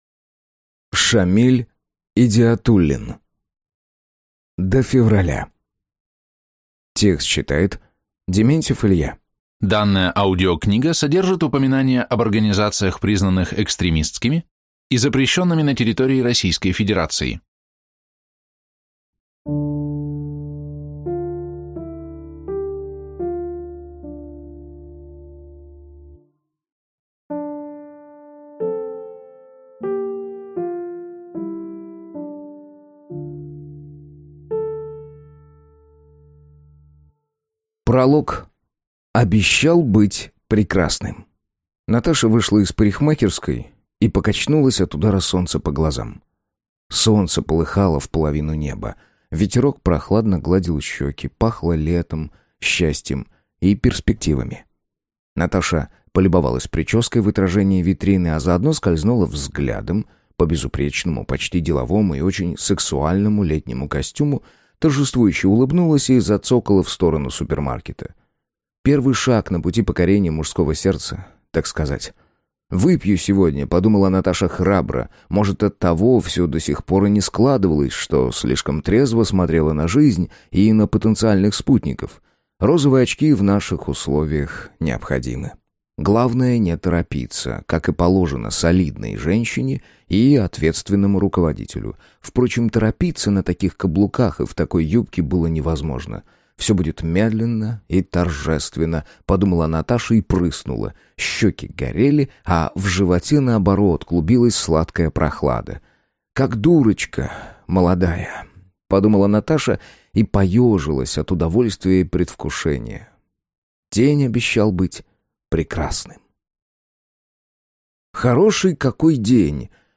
Аудиокнига До февраля | Библиотека аудиокниг